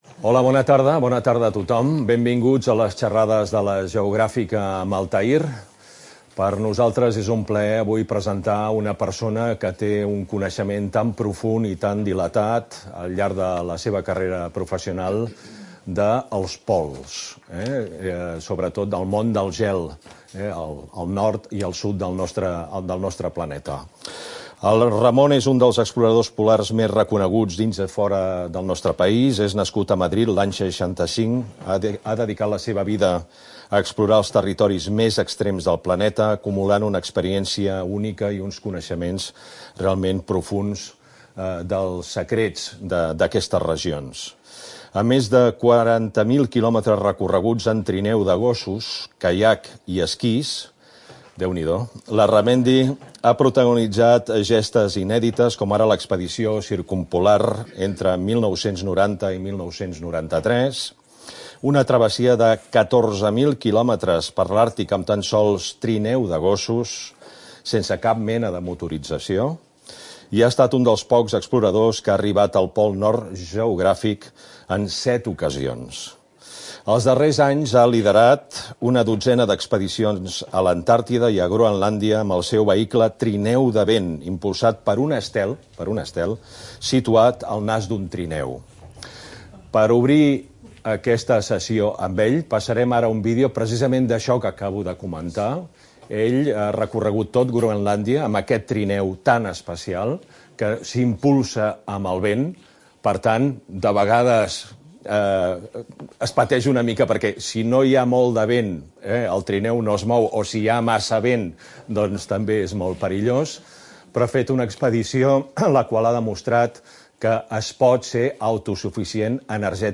xerrada